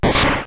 P3D / Content / Sounds / Battle / Damage / Effective.wav
Normalized all SFX by Perceived Loudness (-11.0 LUFS)